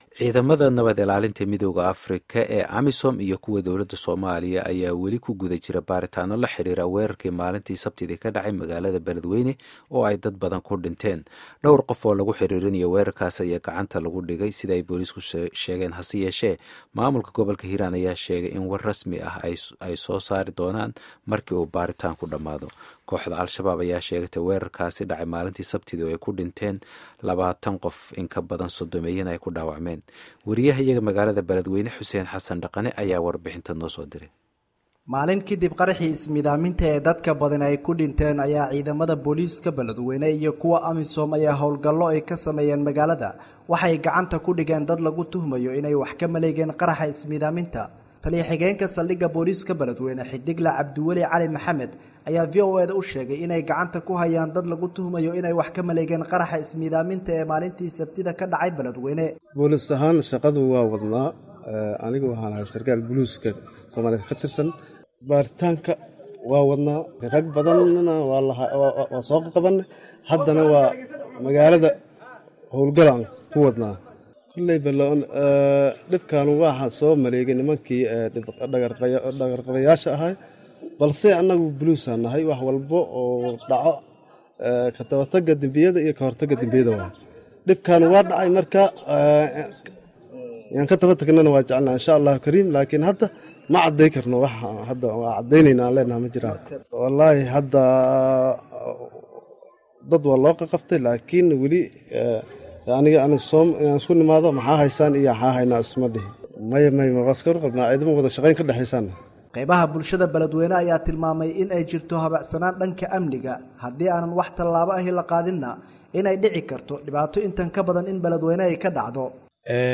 Warbixinta Beledweyne